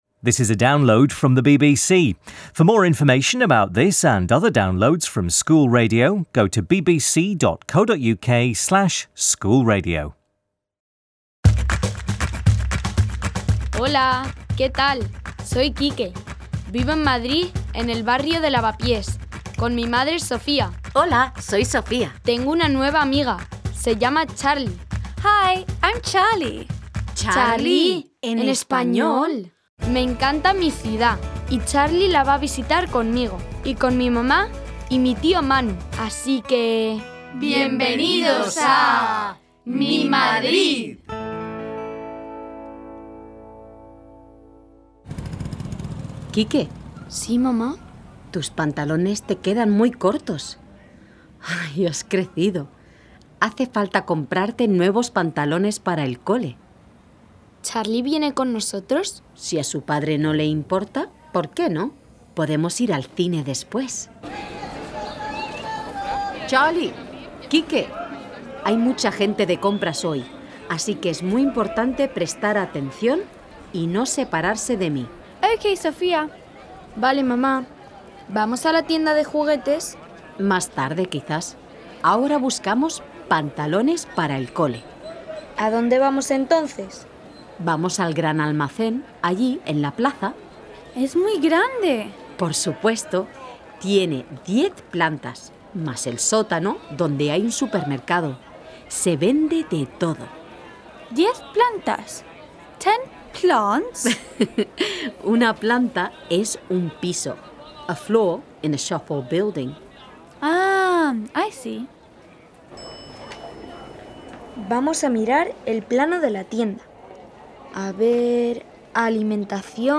Quique needs new clothes to wear so Sofía takes him and Charlie to Madrid's most famous shopping street, the Gran Via. Sofía tells a story about packing for a camping trip and we hear a song about having nothing to wear! The key vocabulary includes items of clothing and key grammar points include the use of definite and indefinite articles.